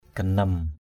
/ɡ͡ɣa-nʌm/ 1.